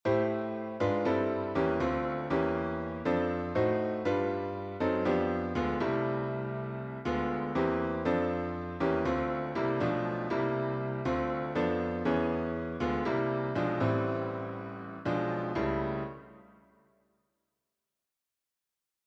Improvisation Piano Jazz